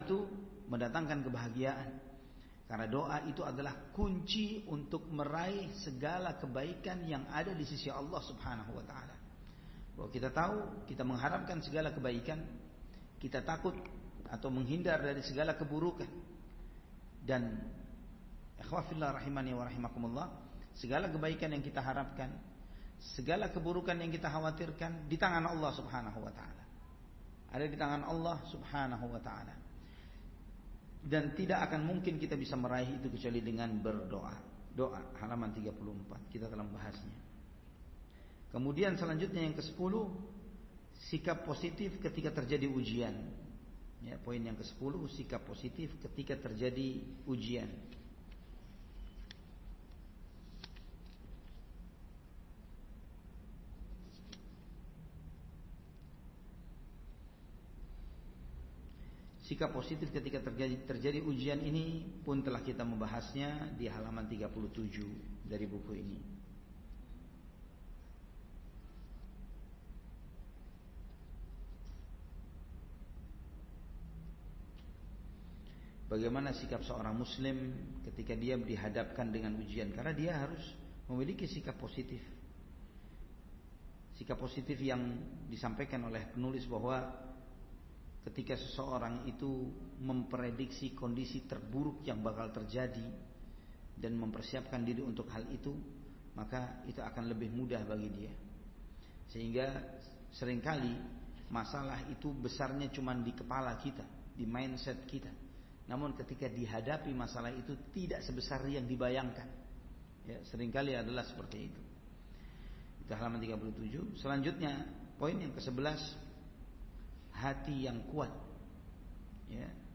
Dalam kajian yang sangat relevan dengan dinamika kehidupan modern ini